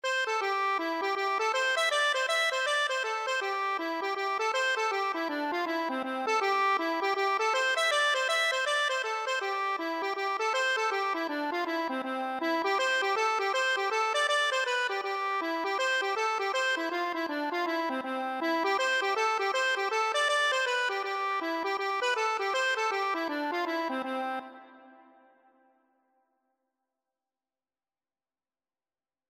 C major (Sounding Pitch) (View more C major Music for Accordion )
4/4 (View more 4/4 Music)
Accordion  (View more Intermediate Accordion Music)
Traditional (View more Traditional Accordion Music)
Irish